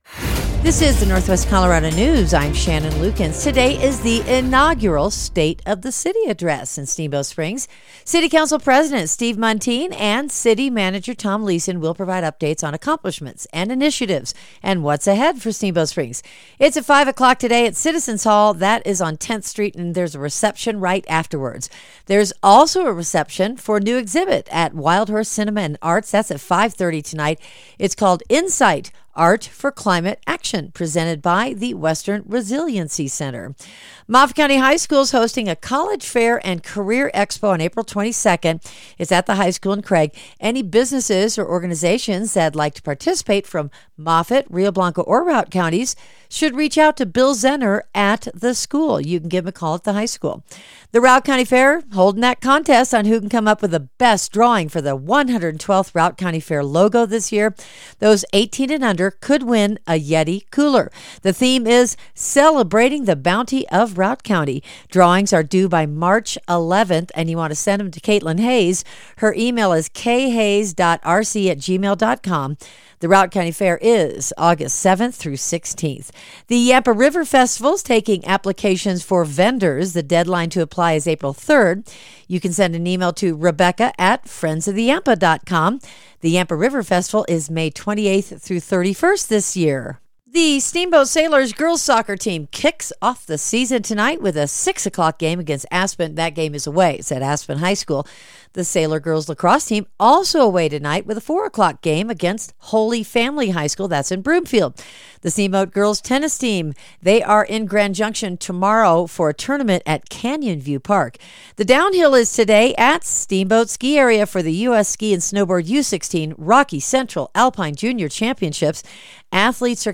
Northwest Colorado News for Thursday, March 5, 2026